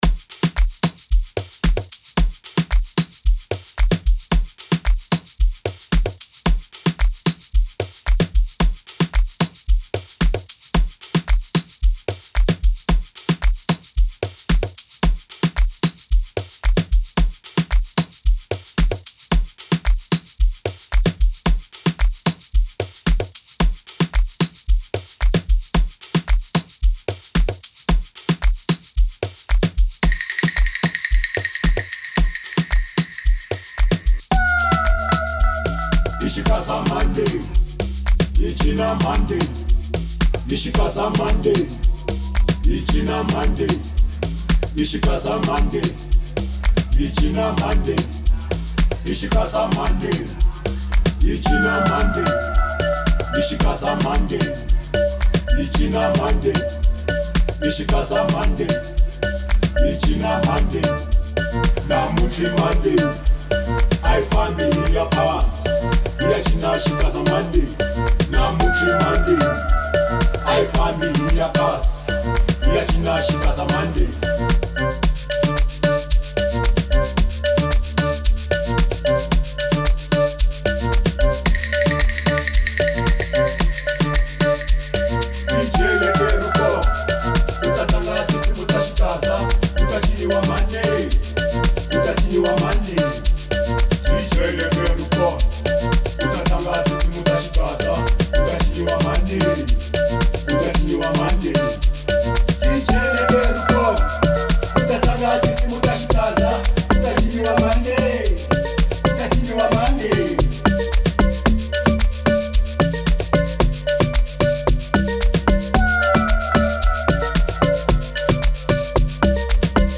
05:32 Genre : Amapiano Size